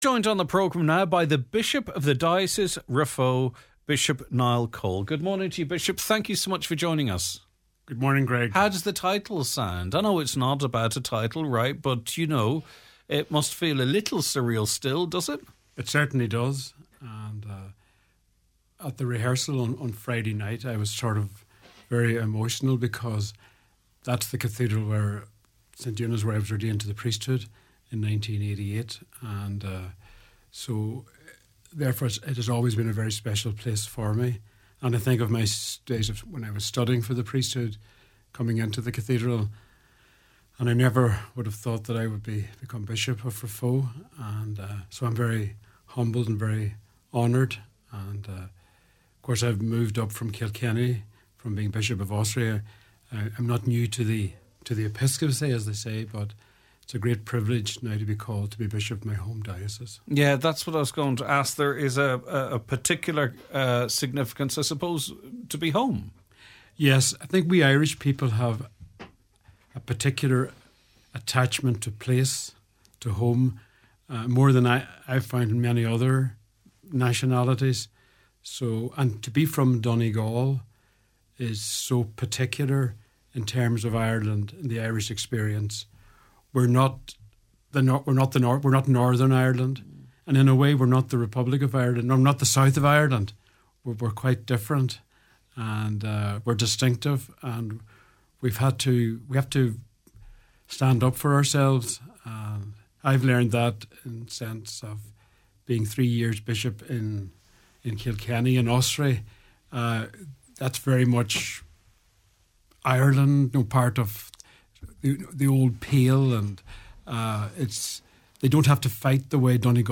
In this episode, we sit down with the newly installed Bishop of Raphoe, Niall Coll.